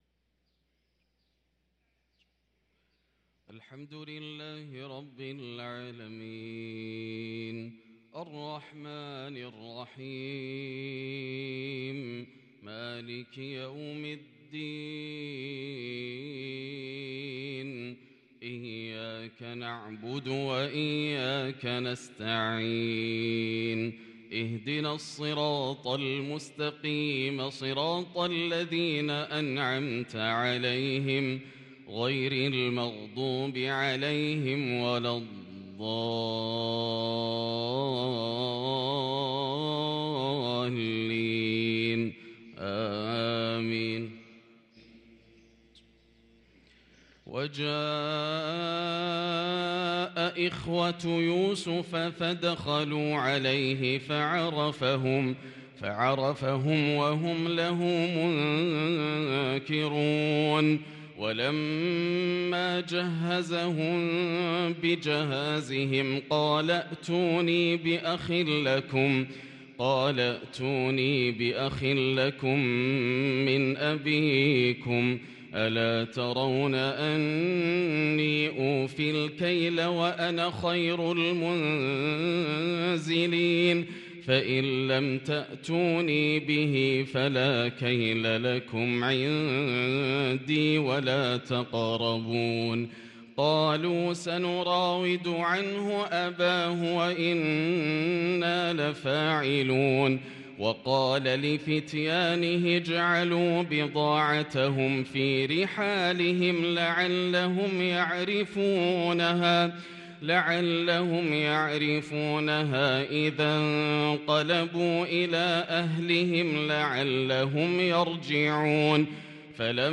صلاة الفجر للقارئ ياسر الدوسري 6 جمادي الأول 1444 هـ
تِلَاوَات الْحَرَمَيْن .